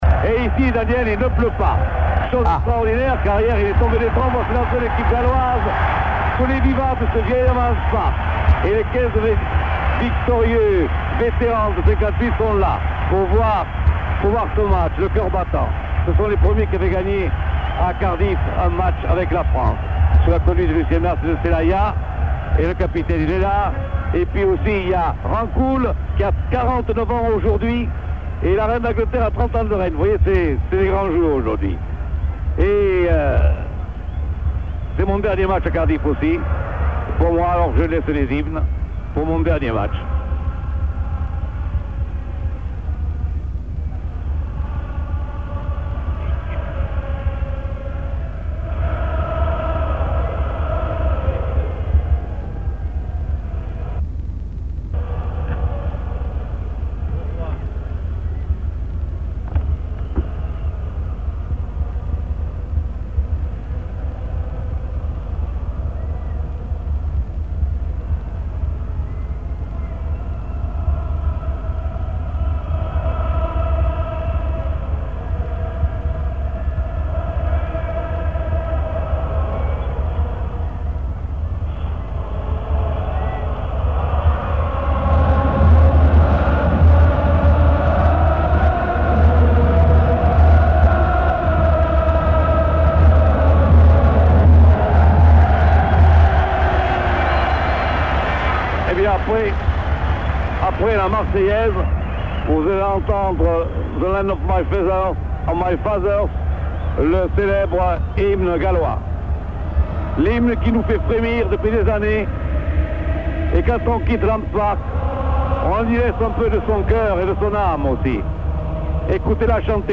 ,,,AUDIO - Commentaire de Roger Couderc et Pierre Albaladejo:
cccccccccccWales-France 1982 :Les hymnes - la Marseillaise et le Land of my Father :